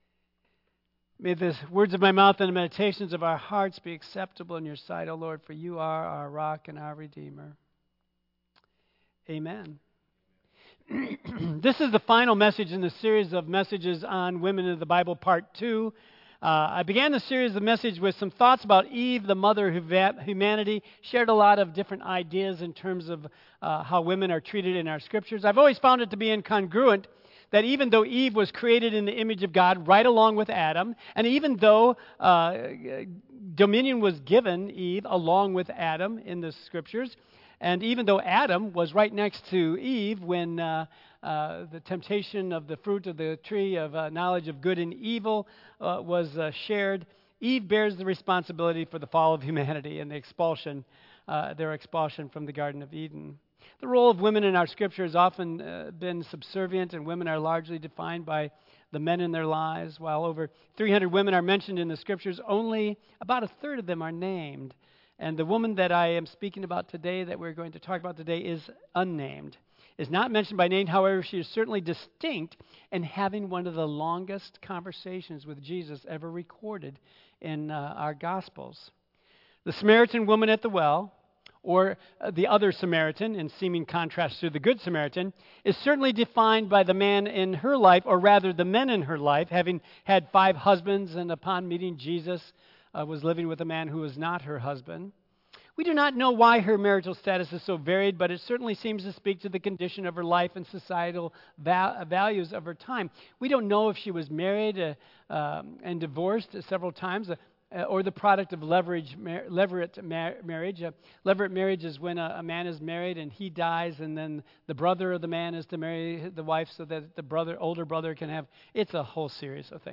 Tagged with Michigan , Sermon , Waterford Central United Methodist Church , Worship Audio (MP3) 9 MB Previous Delilah Next Let Them vs. Let God